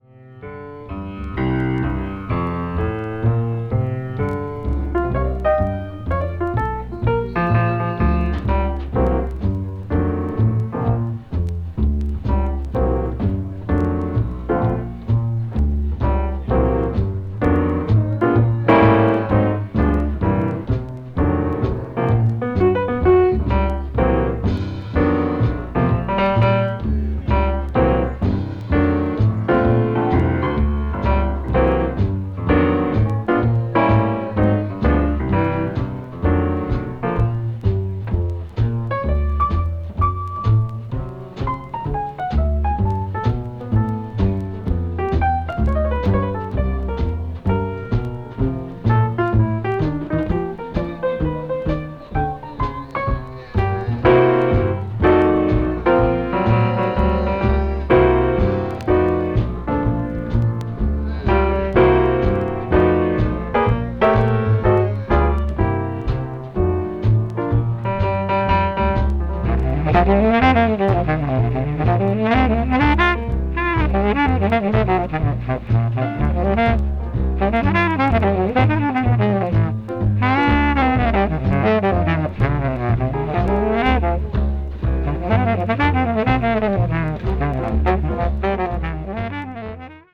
hard bop   modern jazz